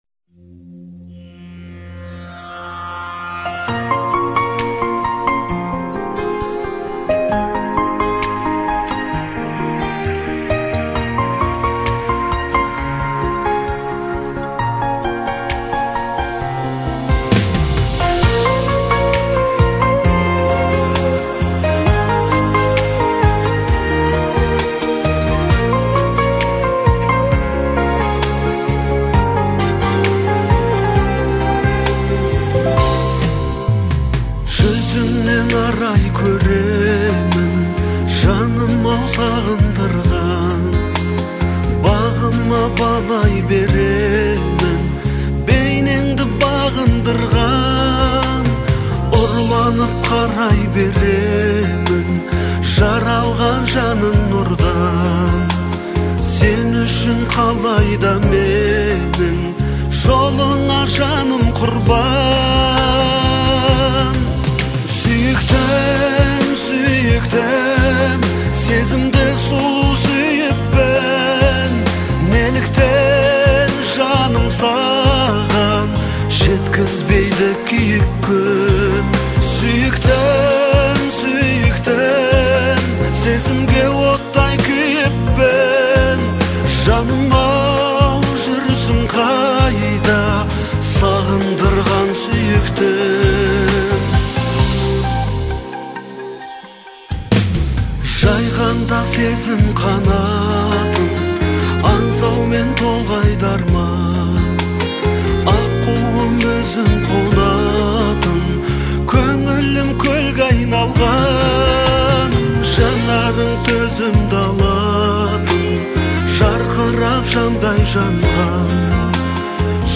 это трогательная баллада в жанре казахской поп-музыки.